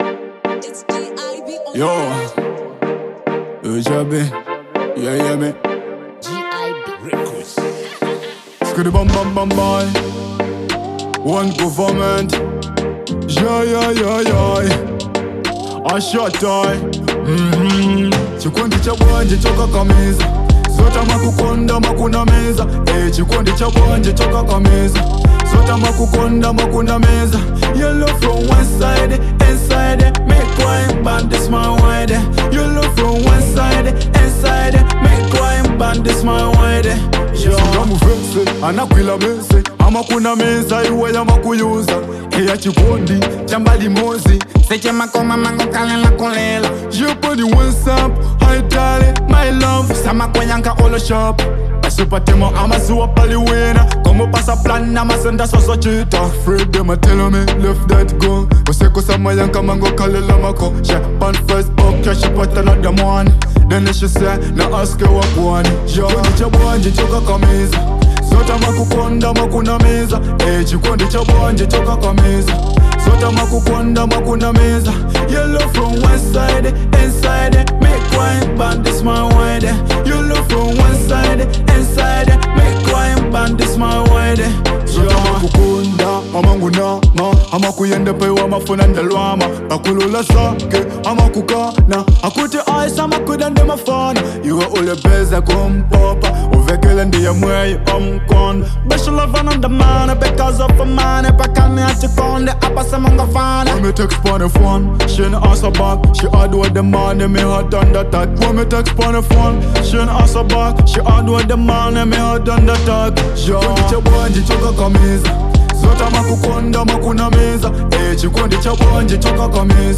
Dancehall • 2025-07-11